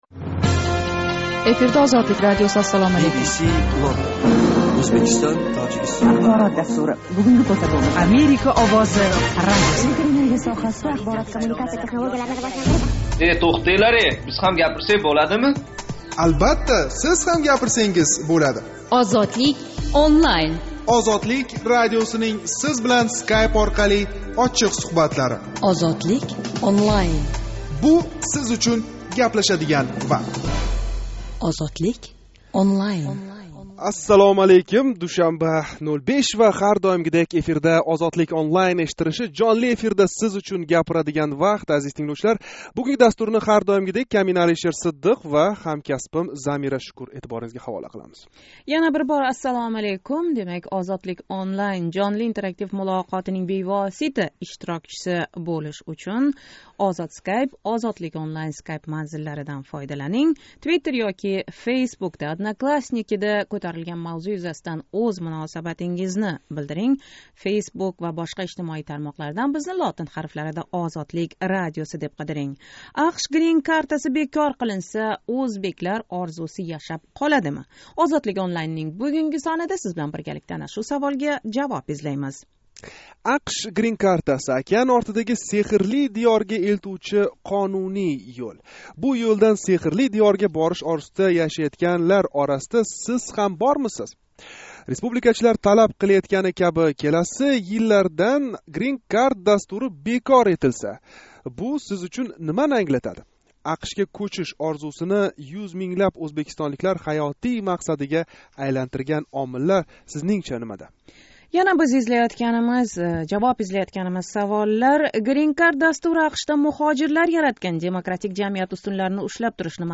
Green Cardнинг бекор бўлиш эҳтимоли¸ океан ортидаги “сеҳрли диëр” орзусида яшаëтган ўзбекистонликлар учун нимани англатади? OzodSkype ва OzodlikOnline Skype манзиллари орқали 20 май Тошкент вақти билан 21:05 да бошланган жонли суҳбатимизда ана шу ҳақда гаплашдик.